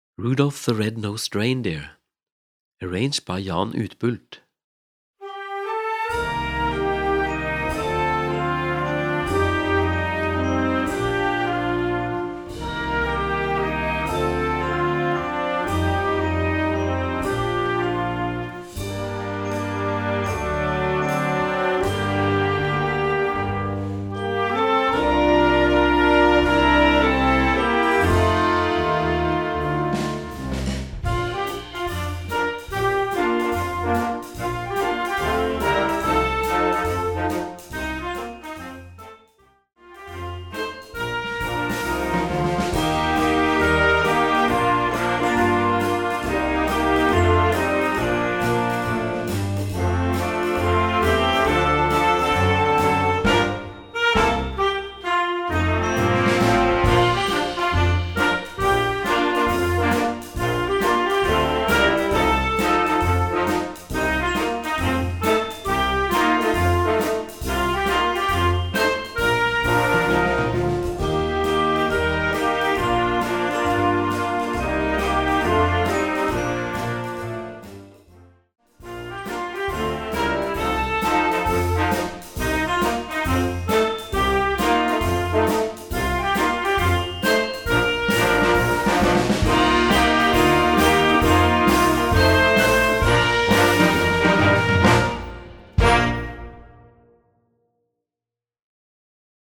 Gattung: Weihnachtsmusik für Jugendblasorchester
Besetzung: Blasorchester